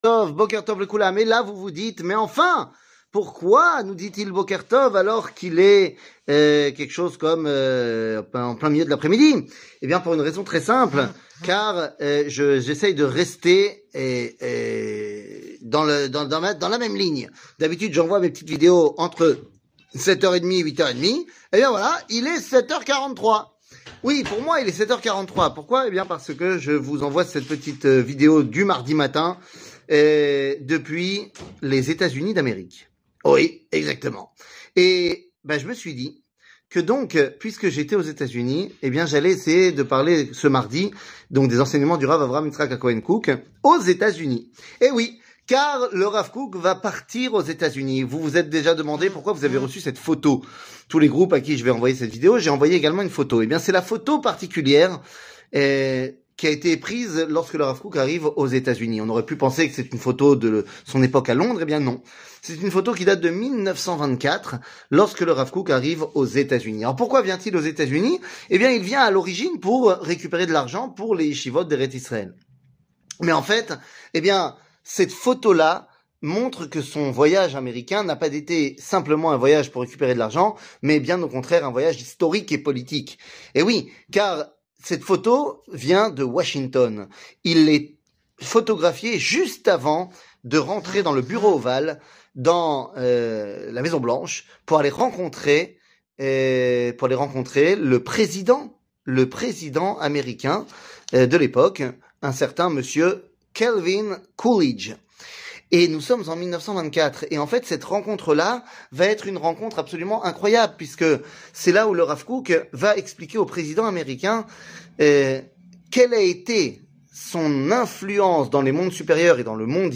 שיעור מ 05 מרץ 2024